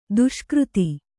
♪ duṣkřti